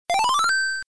levelup.mp3